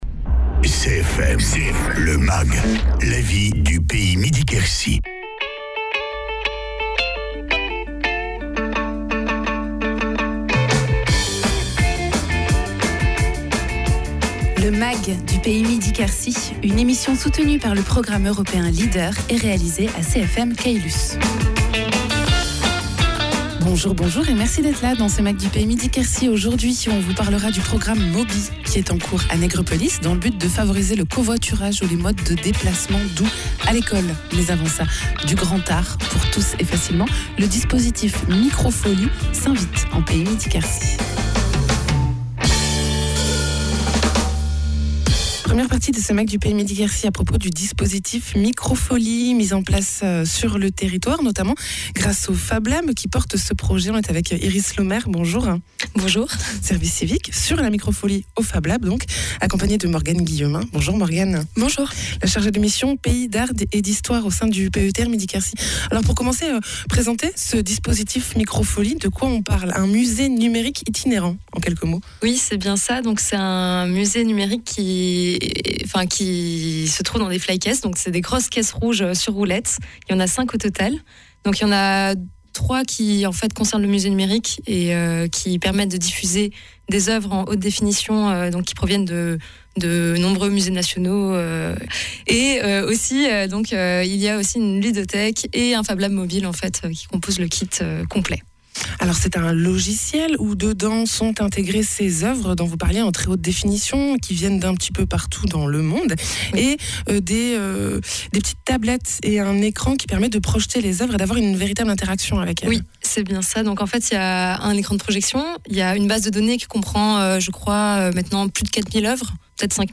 Elodie Combret, adjointe au maire de Nègrepelisse